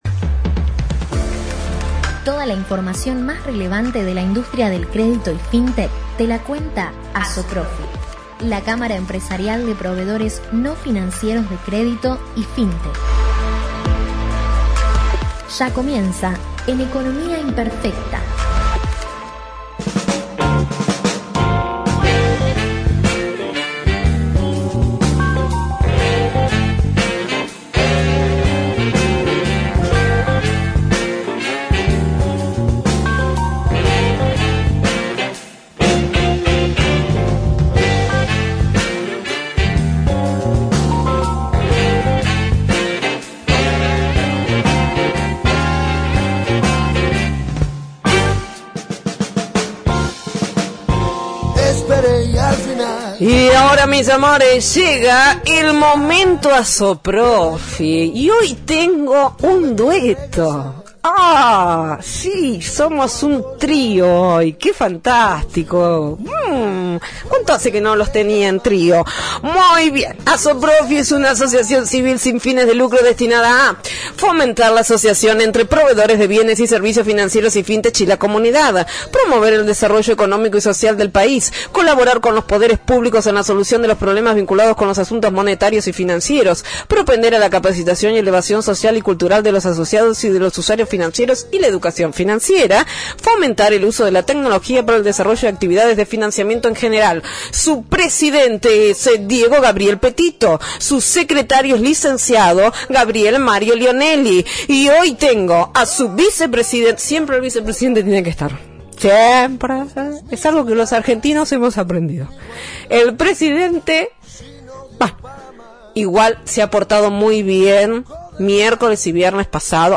ASOPROFI – COLUMNA RADIAL – RADIO AM 1420 Miercoles 17/11/21 – Nuevas Regulaciones al Mercado: